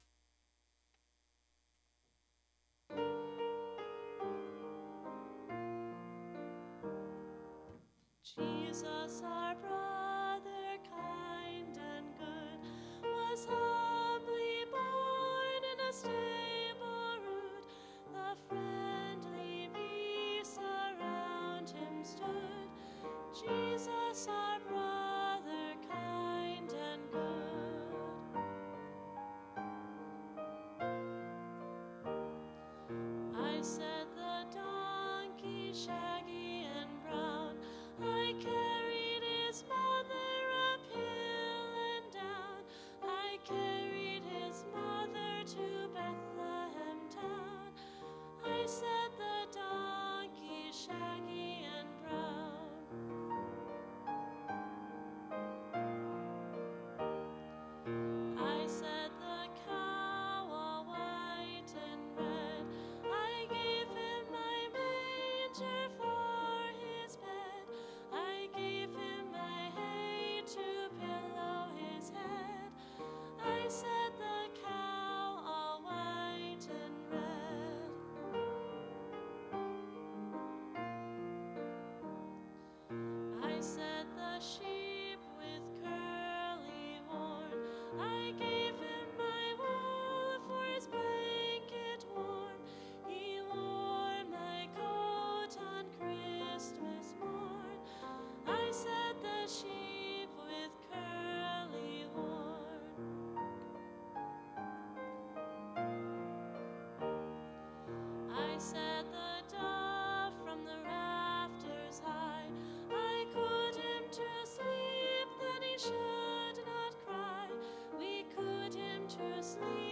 Click here for practice track